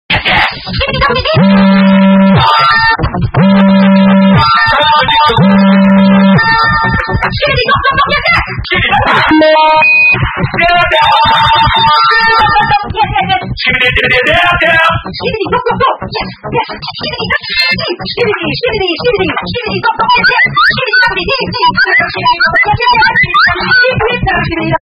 lol sound effects free download